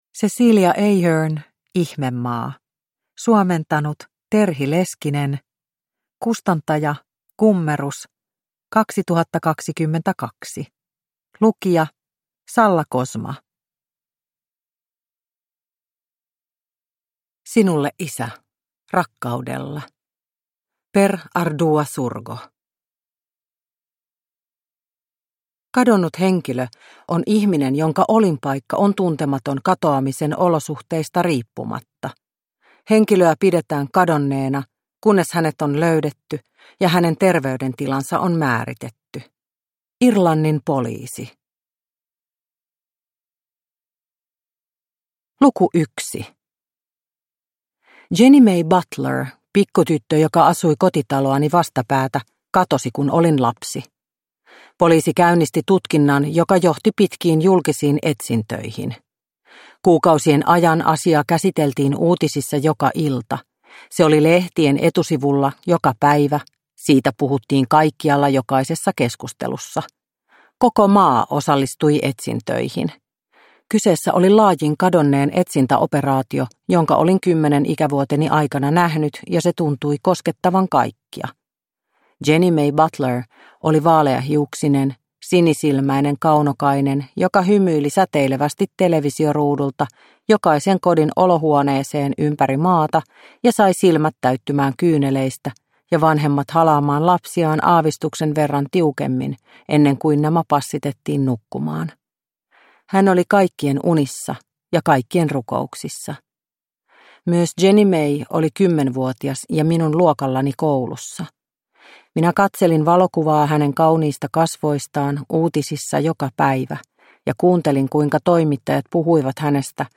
Ihmemaa – Ljudbok – Laddas ner